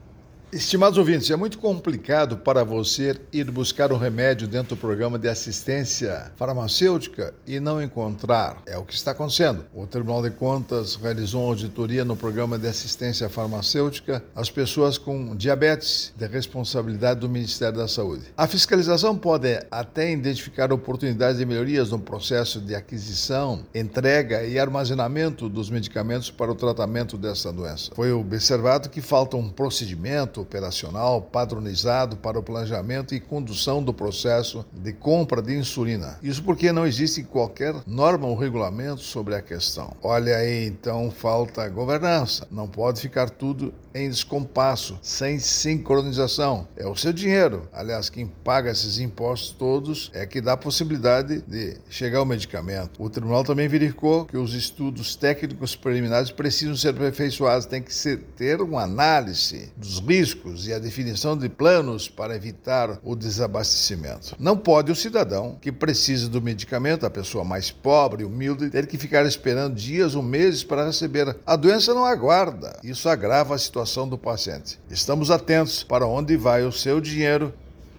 Comentário do Ministro do TCU, Augusto Nardes.